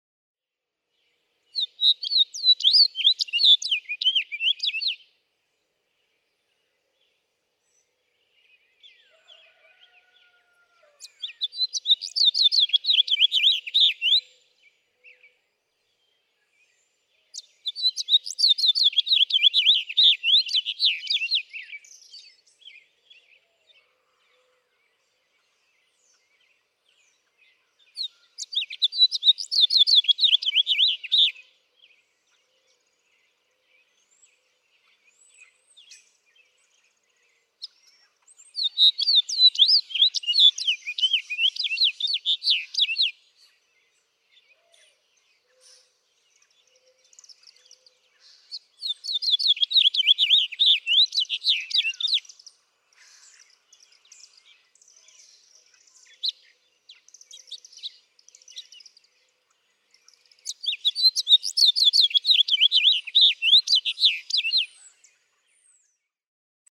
House finch
Normal song, bright, rollicking, cheery, and just a bit hoarse.
Downtown Sebree, Kentucky.
090_House_Finch.mp3